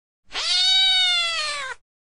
Cat Meow 14 Fx Sound Button - Free Download & Play